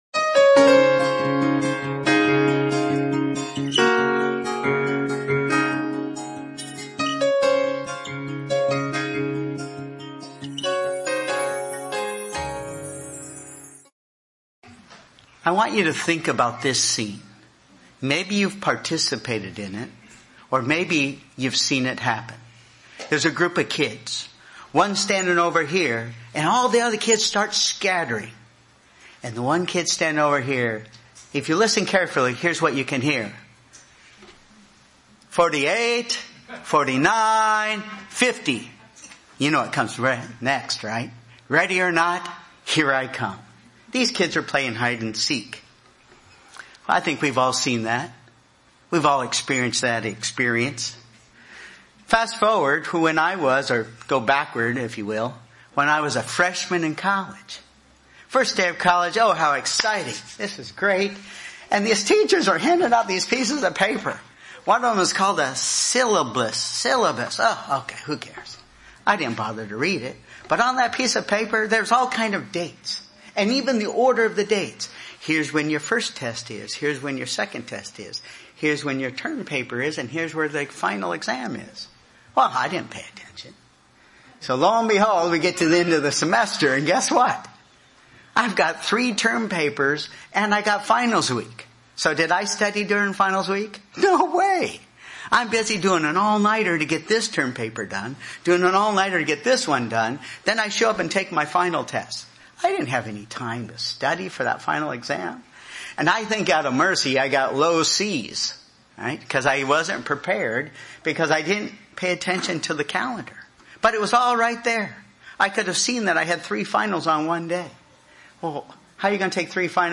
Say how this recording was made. Given in Burlington, WA